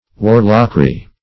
Warlockry \War"lock*ry\, n. Impishness; magic.